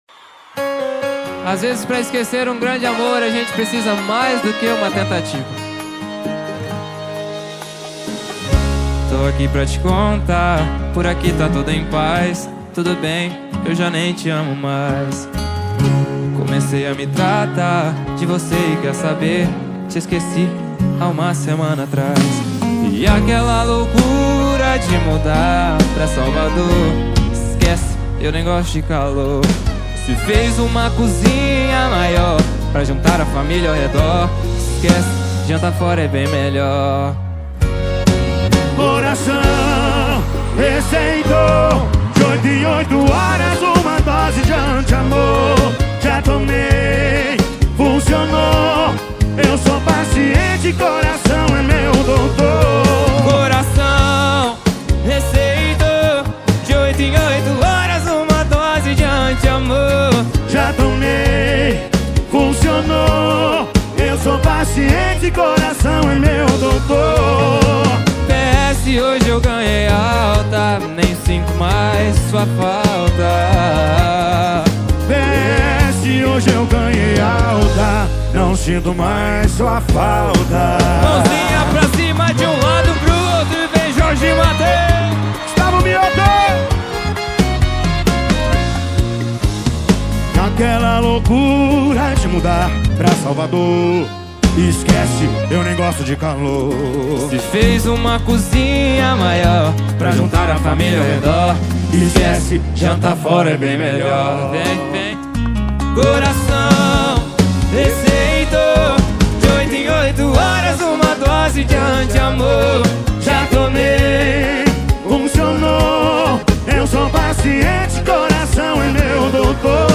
2025-01-27 22:14:50 Gênero: MPB Views